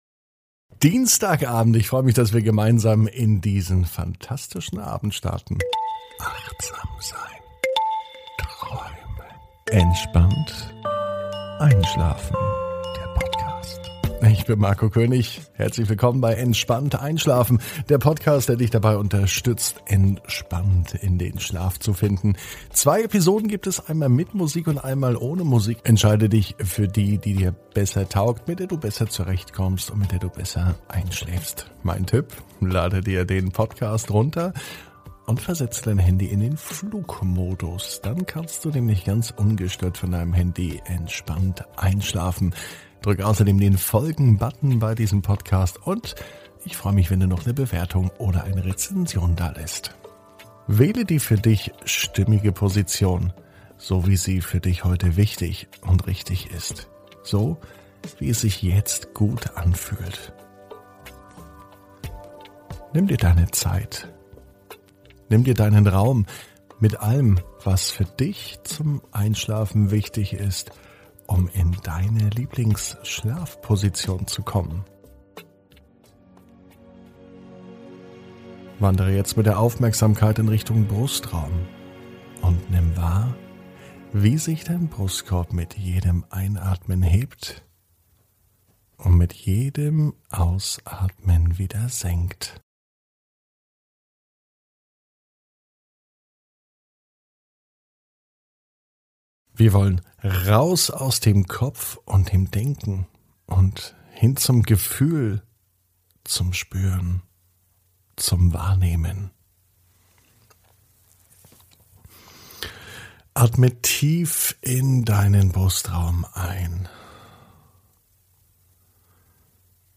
(ohne Musik) Entspannt einschlafen am Dienstag, 18.05.21 ~ Entspannt einschlafen - Meditation & Achtsamkeit für die Nacht Podcast